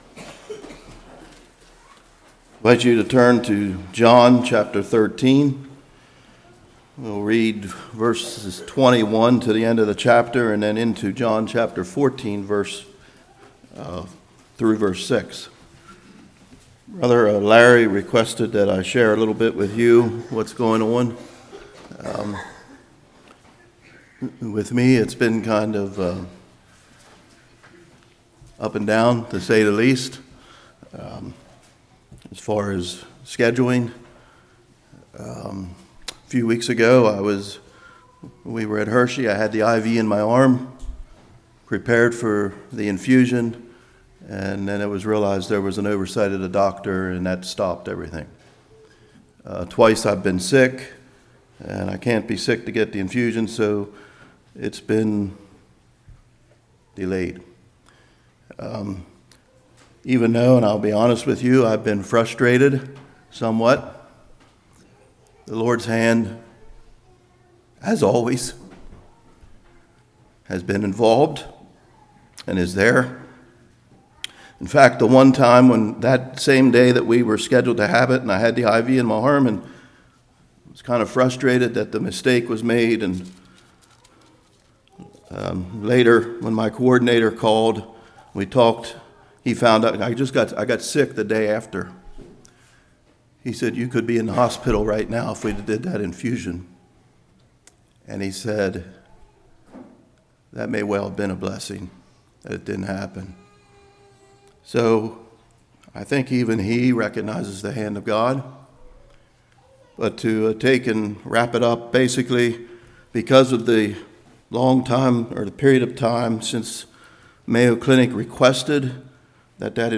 John 13:21-14:6 Service Type: Morning Jesus makes promises.